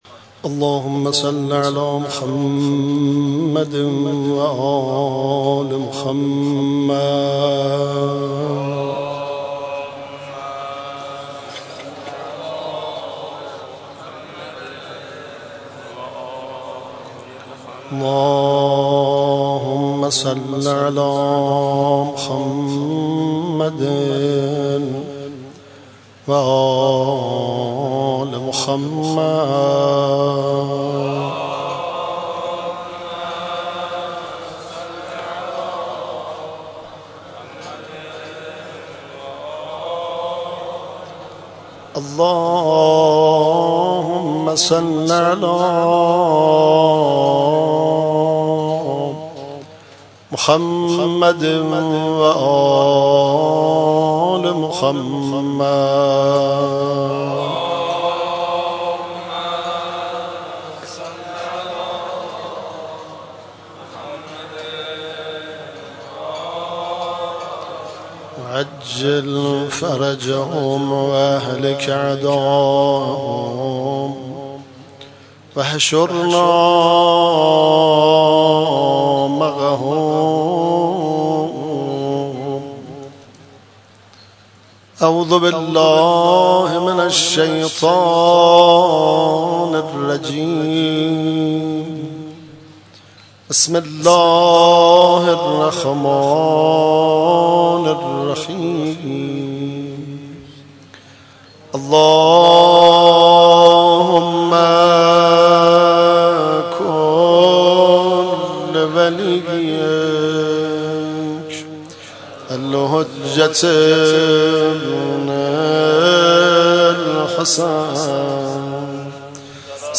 در مسجد شهدا برگزار گردید
قرائت مناجات منظوم حضرت امیر (علیه السلام) ، روضه حضرت قاسم بن الحسن (علیهما السلام)